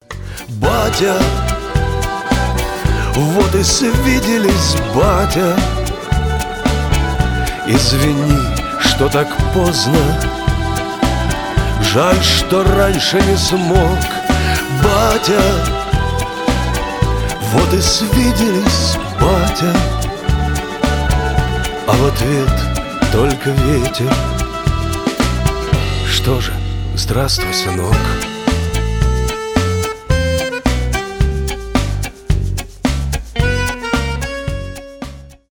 душевные , поп